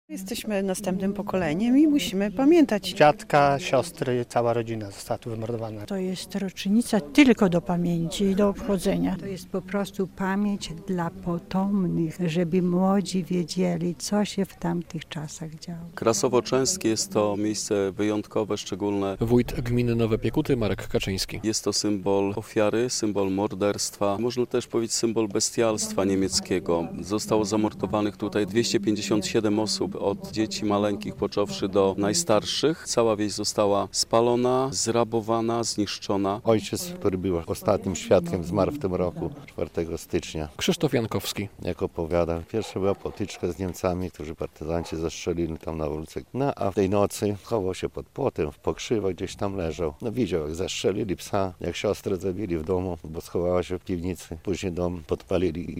Uroczystości we wsi Krasowo-Częstki - relacja